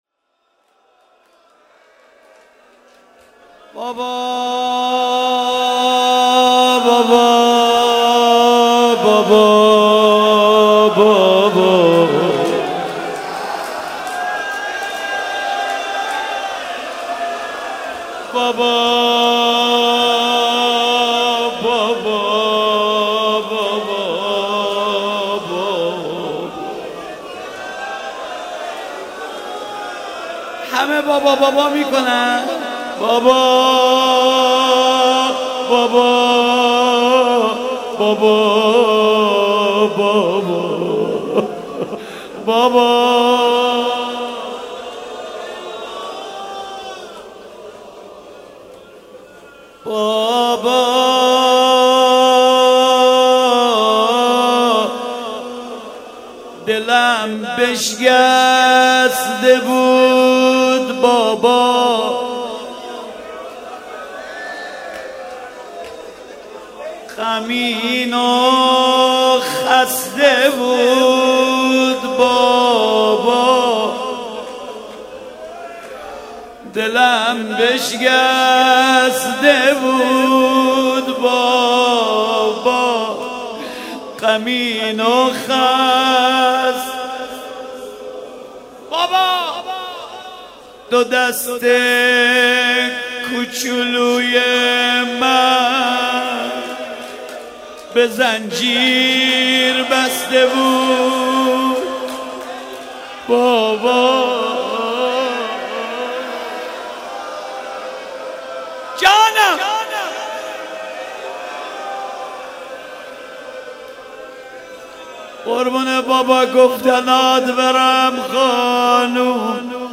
مناسبت : شب سوم محرم
قالب : روضه